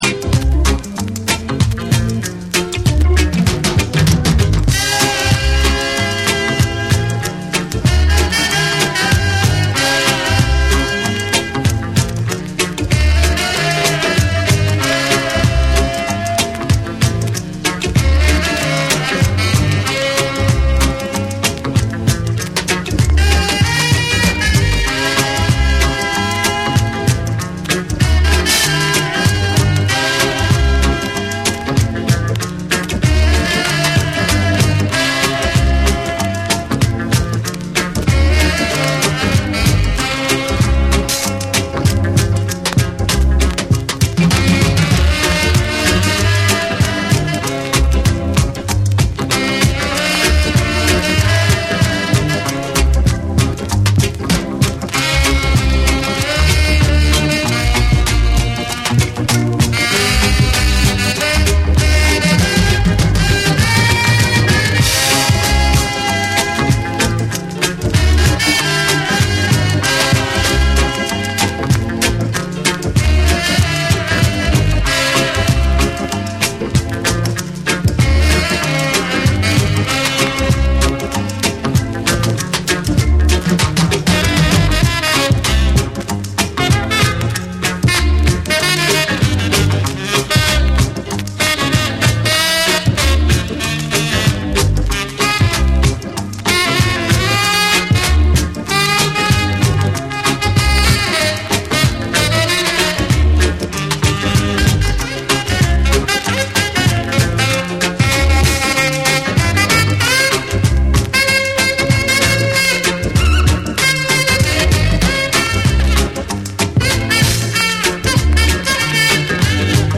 心地よいグルーヴに爽快なサックスが舞うインスト・レゲエを披露！
REGGAE & DUB